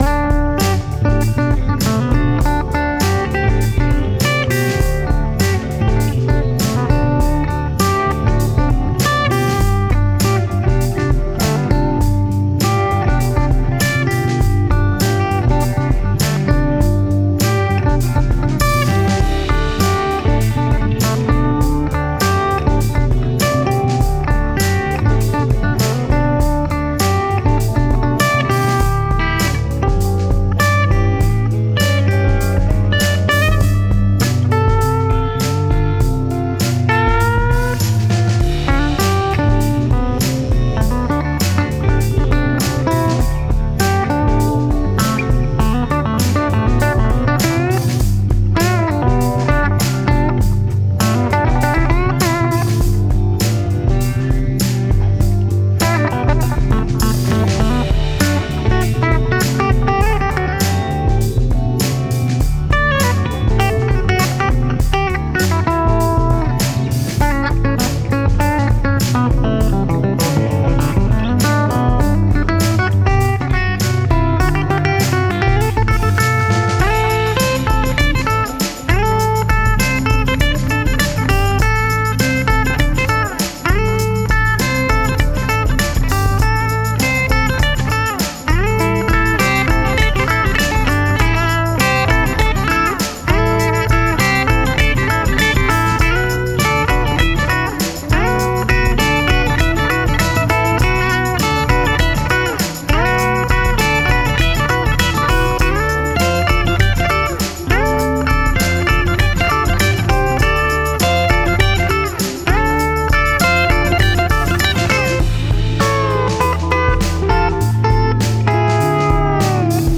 tobacco sunburst Hondo II Les Paul copy
This almost 40-year-old instrument plays and sounds great, delivering a vintage Gibson tone, with classic clarity and crunch courtesy of the DiMarzio bridge pick-up.
Hondo-II-Blues.m4a